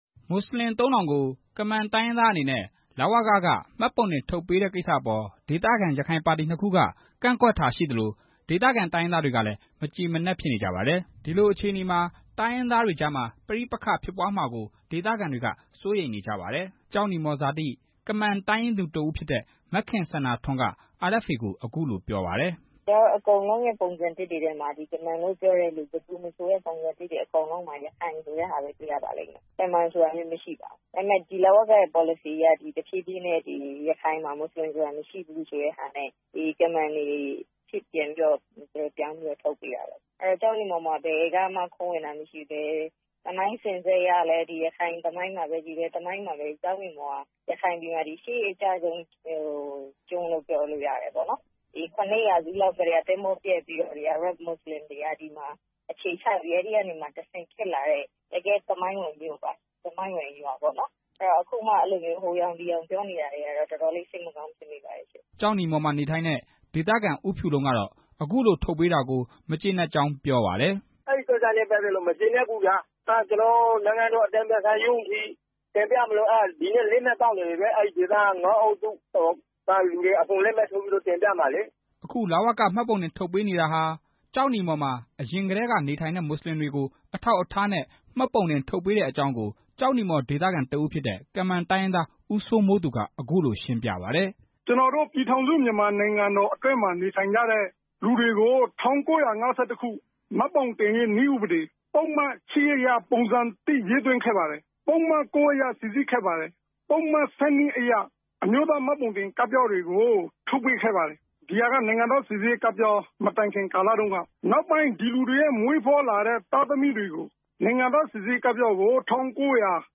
ကျောက်နီမော်က ဒေသခံ ကမန် တိုင်းရင်းသားတွေနဲ့ ဒေသခံ ရခိုင် လူမျိုးတွေရဲ့ အသံကို